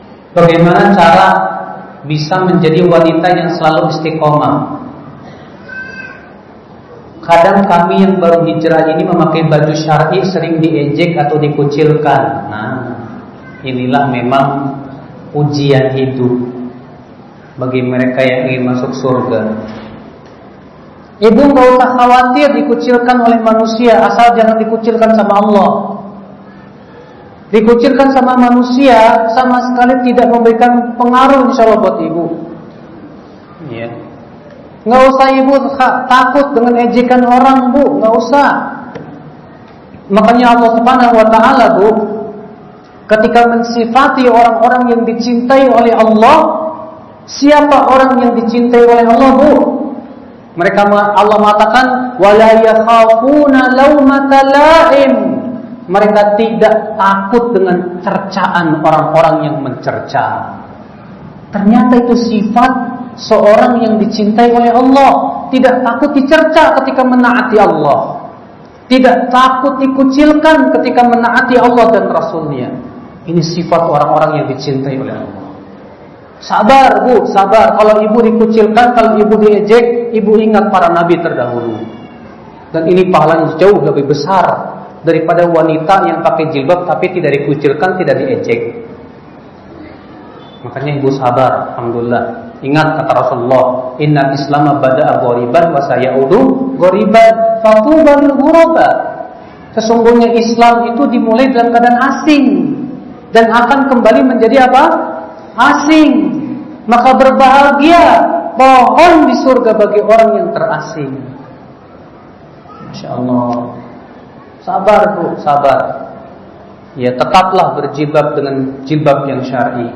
50 Menitan Sesi Tanya Jawab
Sesi tanya jawab seringkali menjadi bagian yang paling ditunggu dalam sebuah kajian, karena di sanalah berbagai permasalahan nyata yang dihadapi umat dikupas tuntas dengan pendekatan yang membumi.